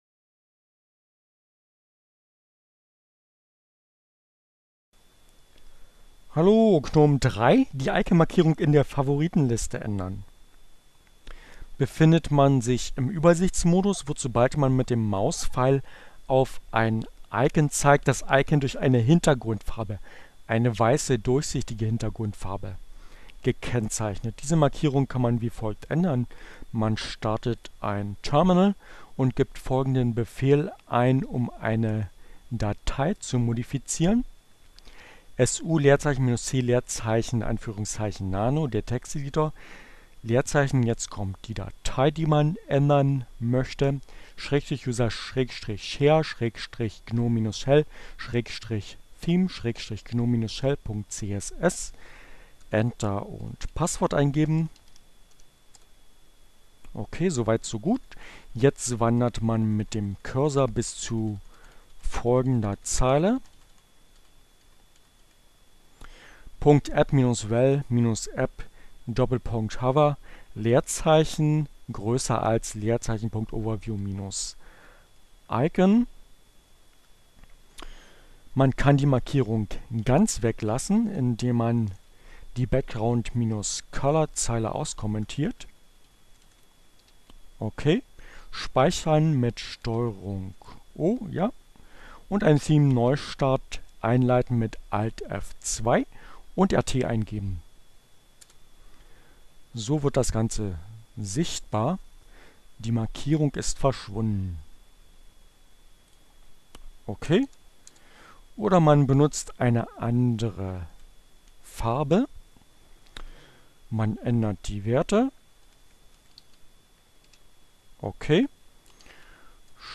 Tags: CC by-sa, Fedora, Gnome, Linux, Neueinsteiger, Ogg Theora, ohne Musik, screencast, gnome3, dash